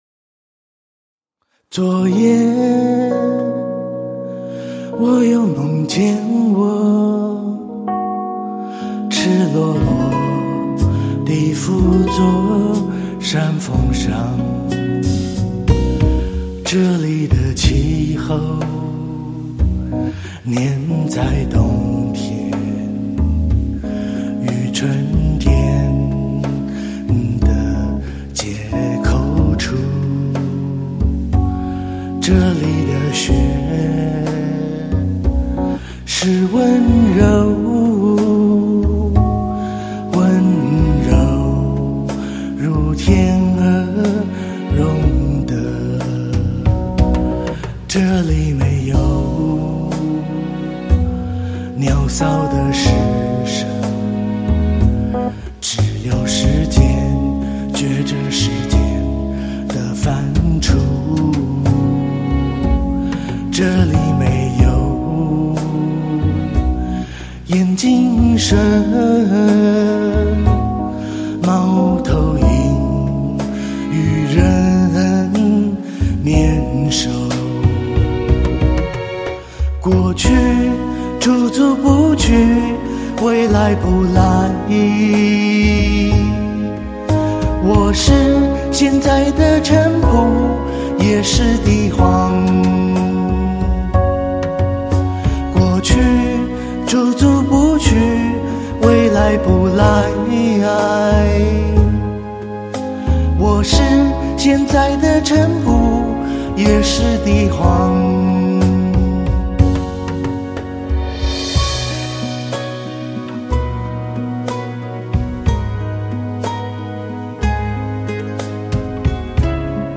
Chinese pop, Pop folk, Electropop/Technopop